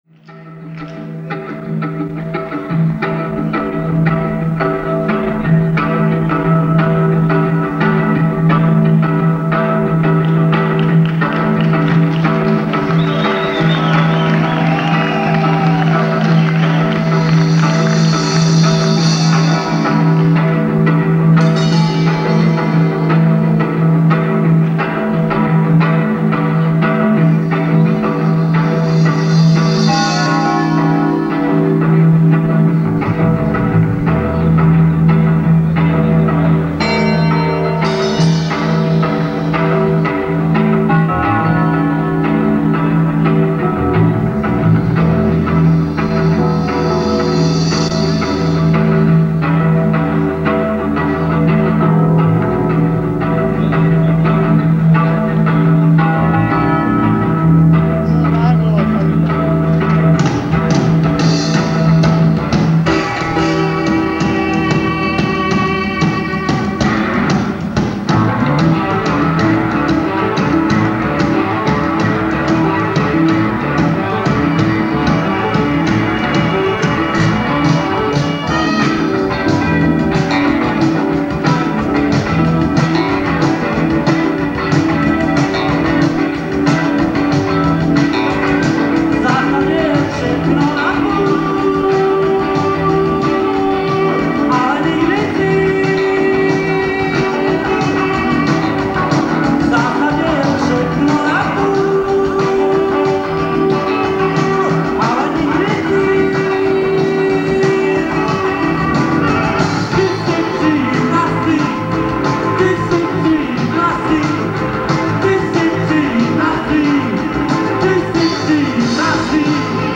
/ Hraju tam totiž na basu /... 8) :D